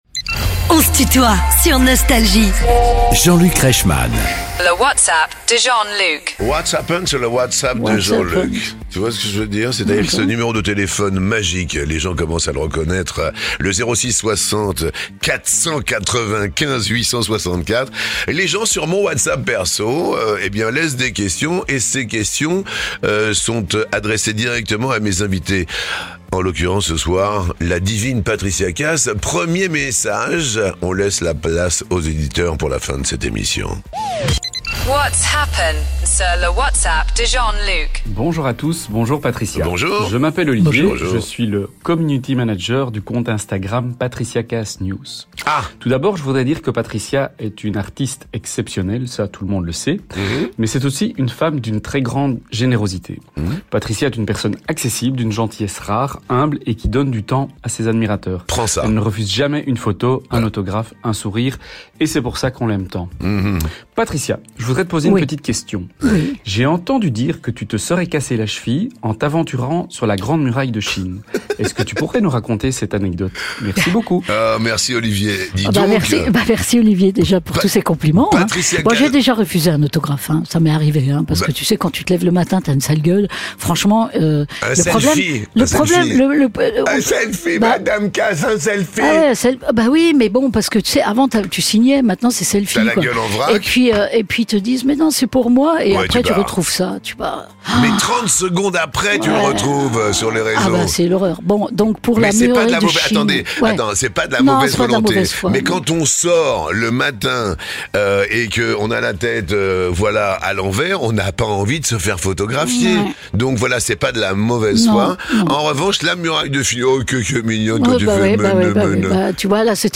Les interviews
Les plus grands artistes sont en interview sur Nostalgie.